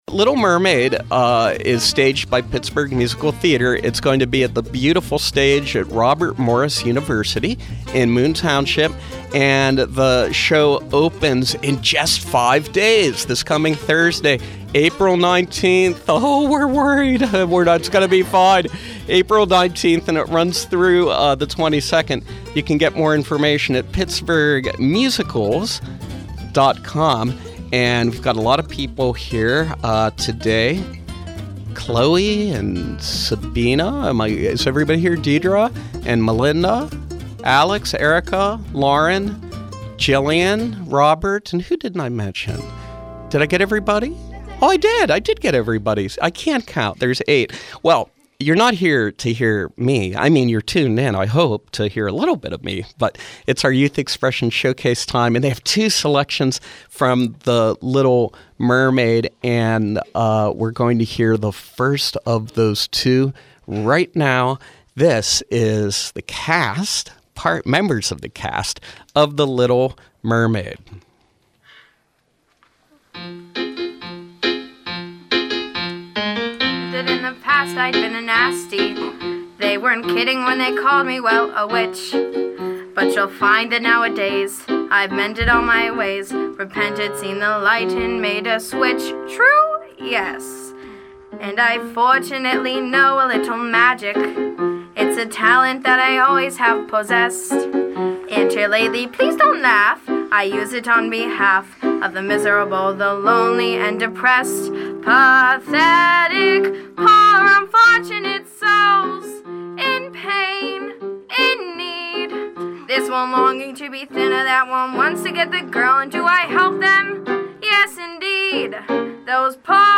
Cast members of Pittsburgh Musical Theater’s production of Selections from ‘Little Mermaid,’ join us to preview the performance.